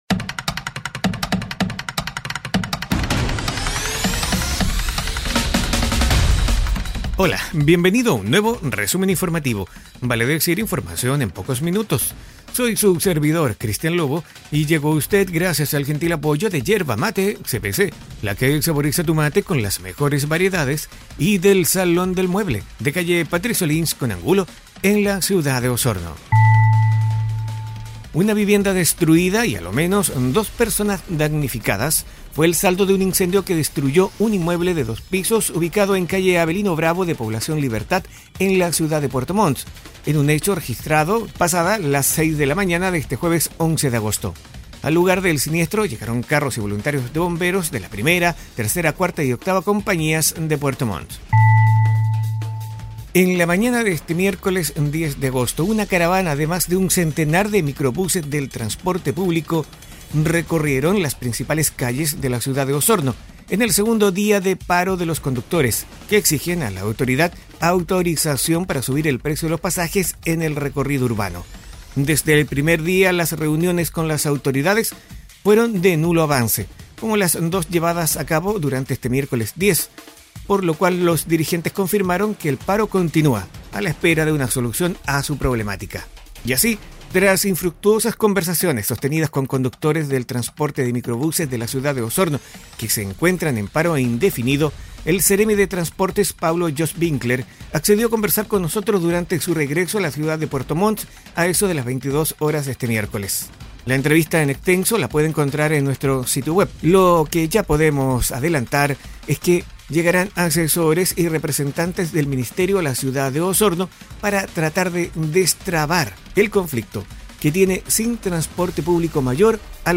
Resumen Informativo 🎙 Podcast 11 de agosto de 2022